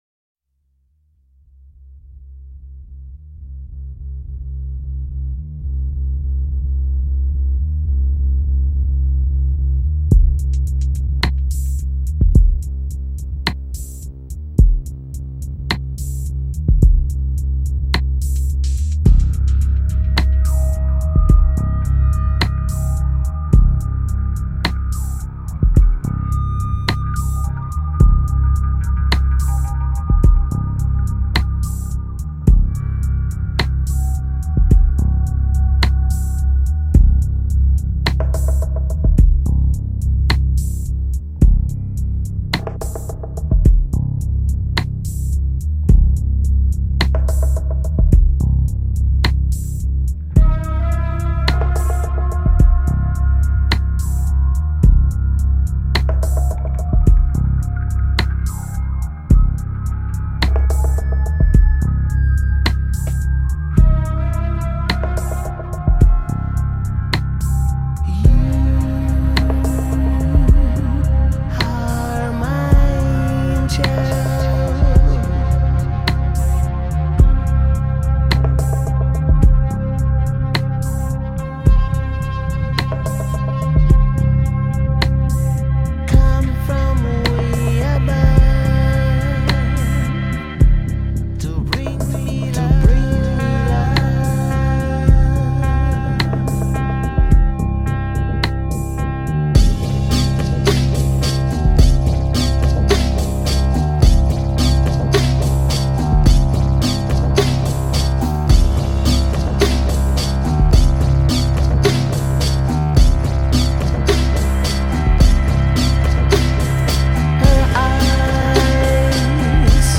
Trip Hop, Electronic